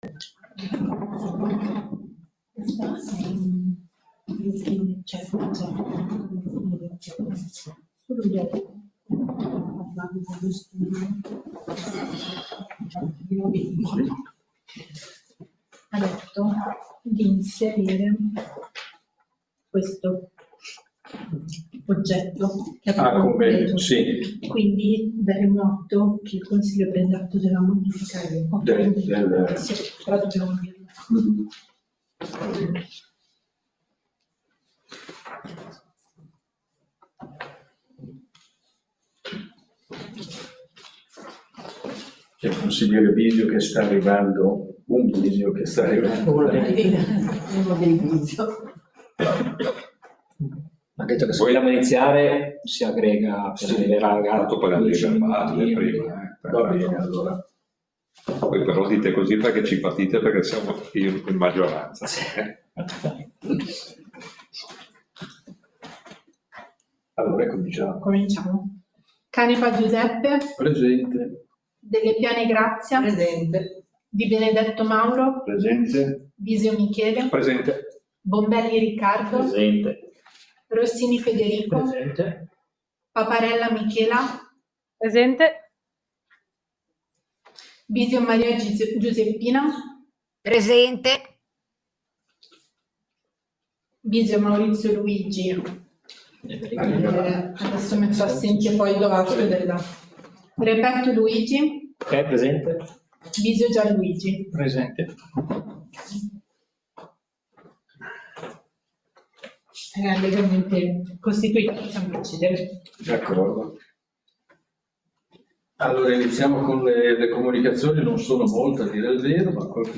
Seduta del Consiglio Comunale del 27/11/2025